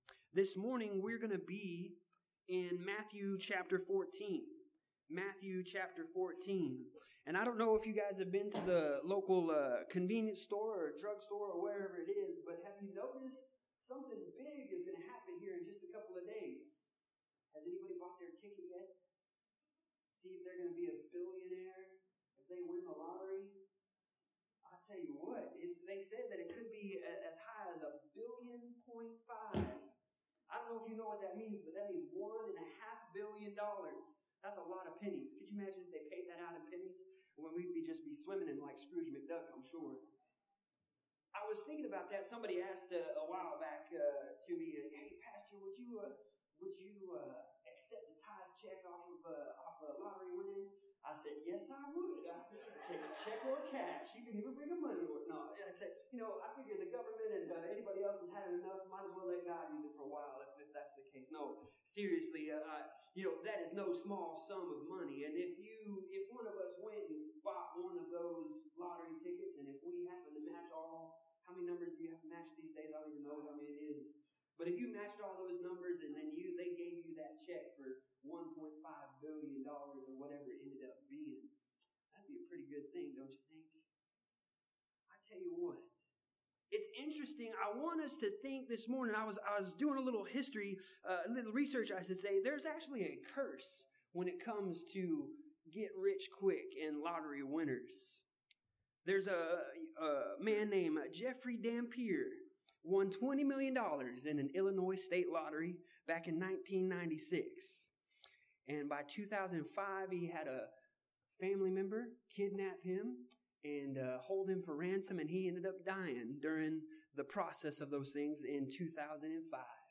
Service Type: Sunday Morning
Sermon1021.mp3